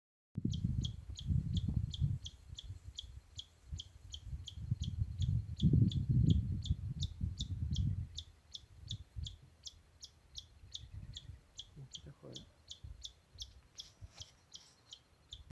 Greater Thornbird (Phacellodomus ruber)
Country: Argentina
Province / Department: Entre Ríos
Condition: Wild
Certainty: Observed, Recorded vocal